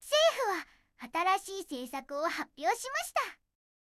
referenceの音声と書き起こしを入力して、Targetのテキストを生成してみました。結構いい感じですが、起伏がオーバーになることが多いのが少し気になりました。
色々パラメータ変えて出力を見てみました。全部同じじゃないですか！